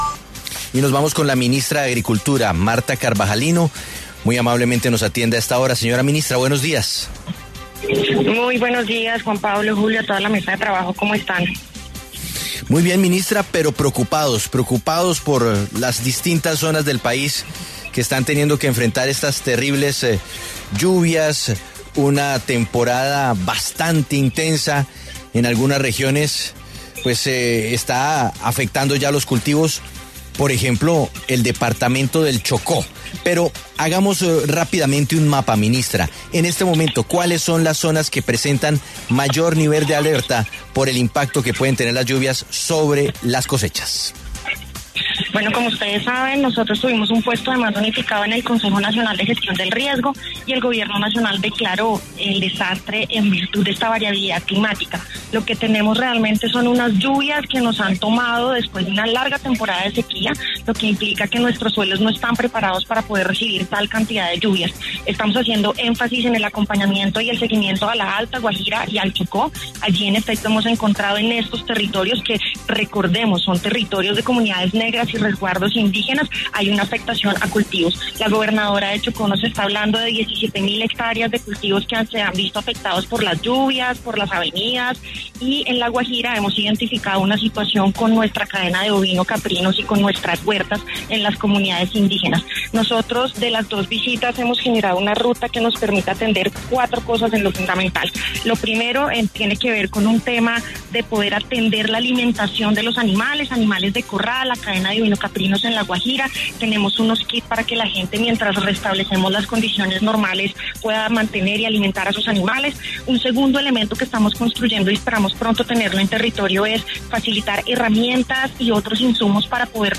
En entrevista con W Radio, la ministra de Agricultura, Martha Carvajalino, entregó un balance sobre el impacto que ha tenido, en el campo colombiano, la ola invernal que azota a diferentes regiones del país, explicando a la vez, las medidas que se están adoptando para poder brindar ayudas a todos los afectados.